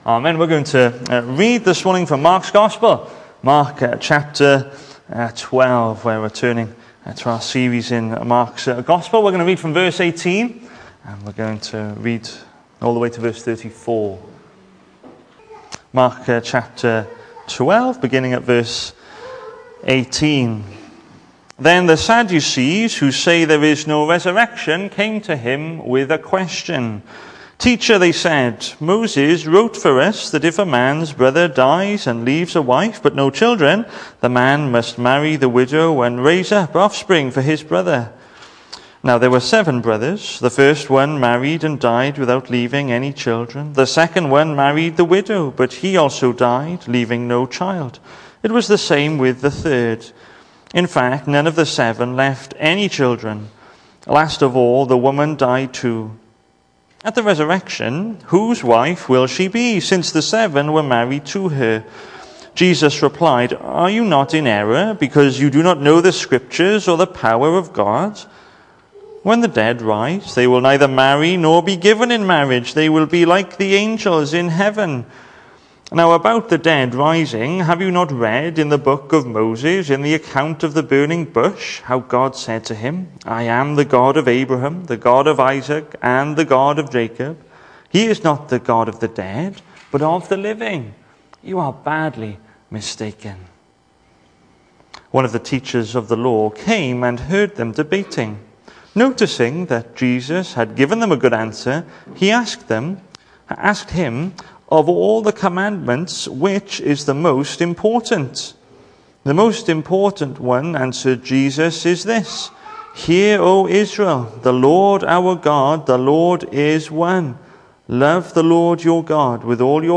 Hello and welcome to Bethel Evangelical Church in Gorseinon and thank you for checking out this weeks sermon recordings.
The 24th of August saw us host our Sunday morning service from the church building, with a livestream available via Facebook.